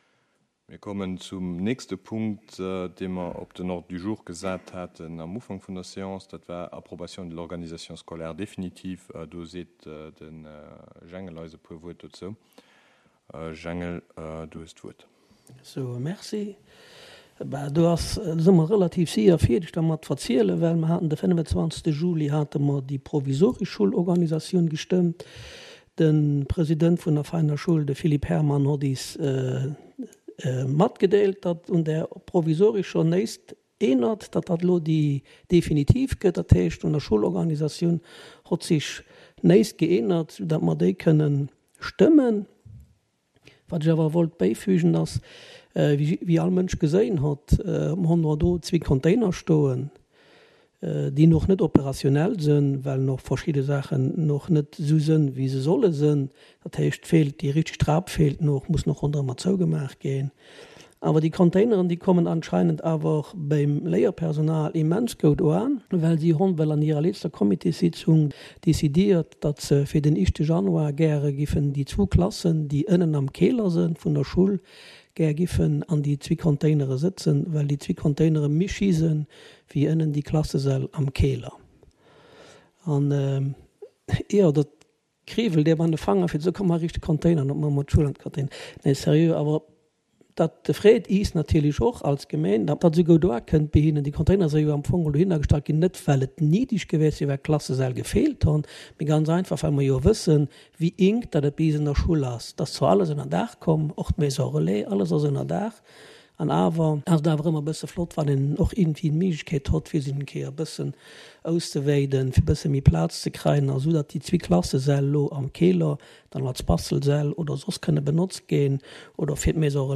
Conseil Communal  du mercredi 28 septembre 2022 à 19h00 heures Centre Culturel Larei en la salle Bessling